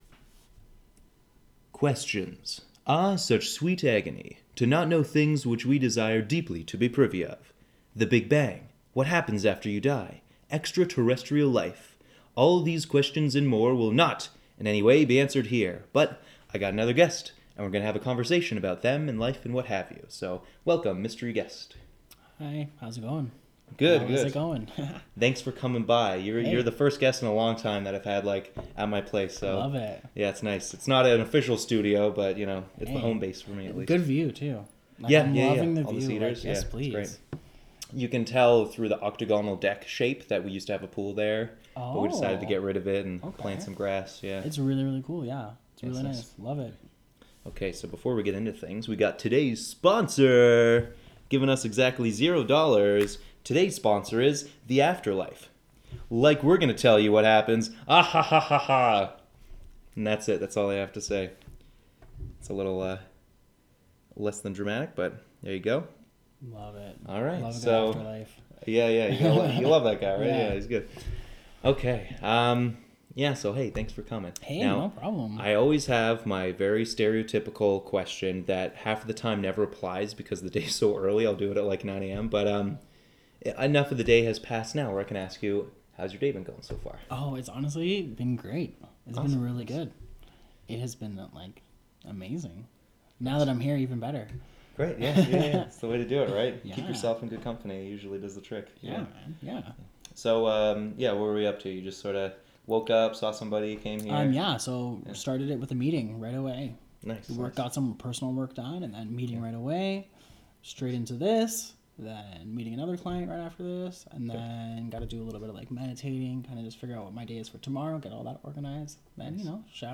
I talk with a fellow self employed, recovered alcoholic about the journey one takes to better their lives. We discuss addiction, scammers, persistence, cycles of bitter habit and finally taking that leap.